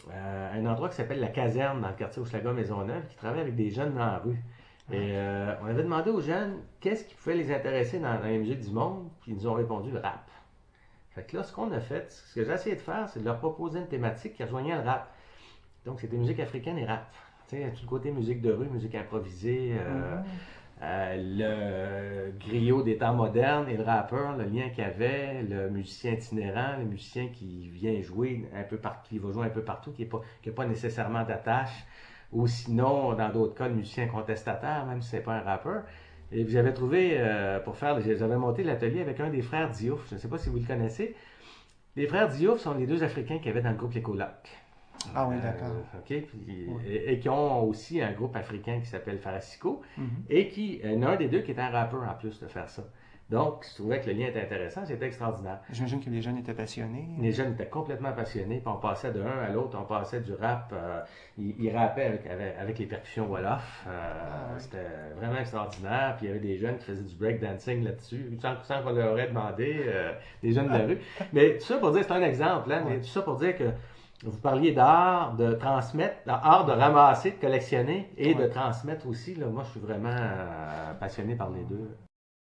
Extraits sonores des invités
Extraits des entrevues sur le patrimoine sonore :